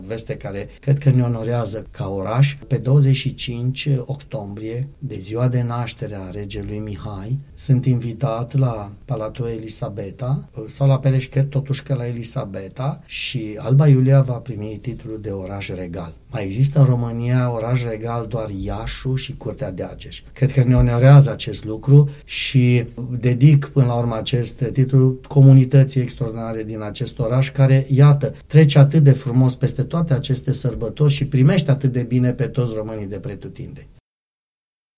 AUDIO | Primarul Gabriel Pleșa, la Unirea FM: Alba Iulia va primi titlul de oraș regal
Informația a fost oferită în premieră, la Unirea FM, de primarul Gabriel Pleșa.